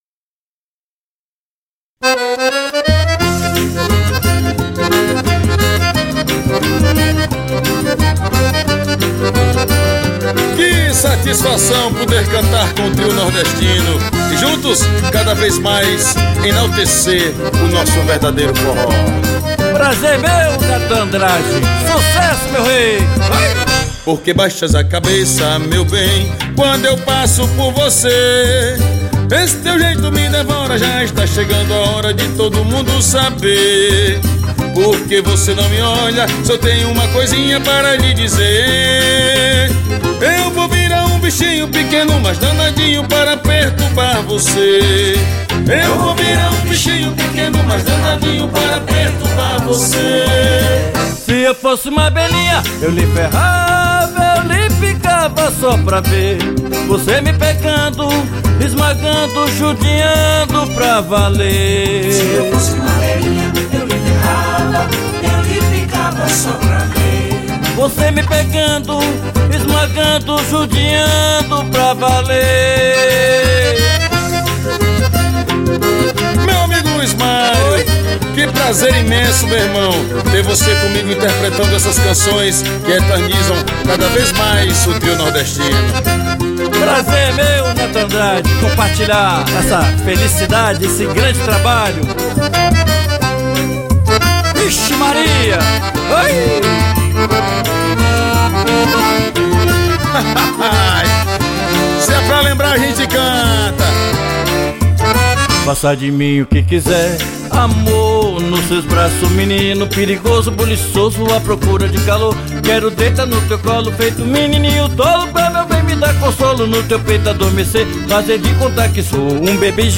pout-porri